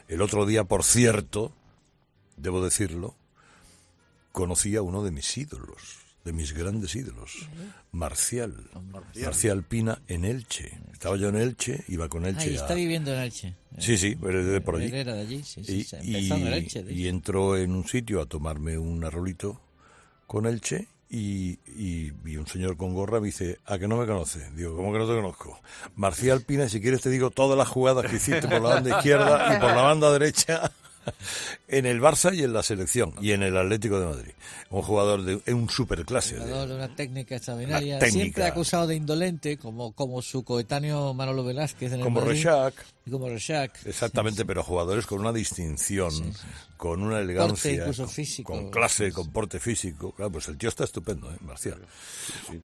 El comunicador ha explicado en 'Herrera en COPE' el momento en el que conoció a uno de sus ídolos futbolísticos
Carlos Herrera explica cómo ha conocido a Marcial Pina en un bar de Elche